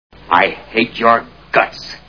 Mister Roberts Movie Sound Bites